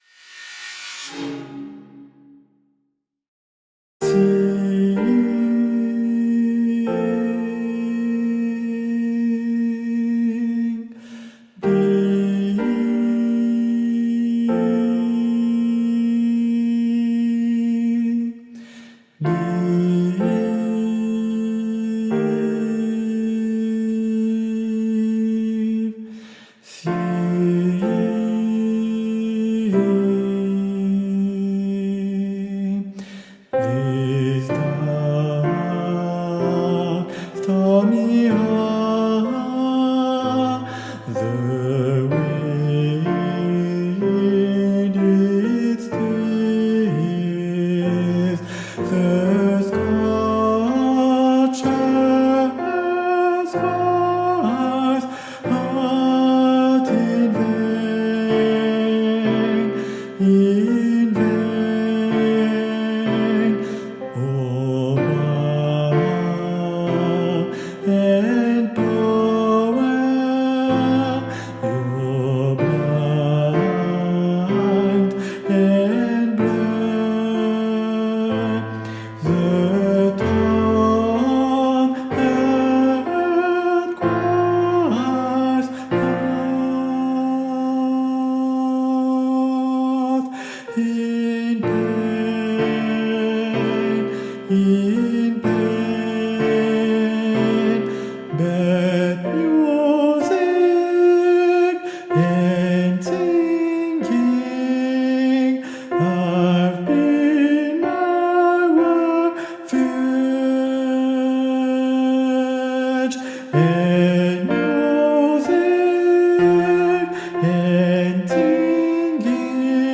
- Chant a capella à 4 voix mixtes SATB
Guide Voix Sopranos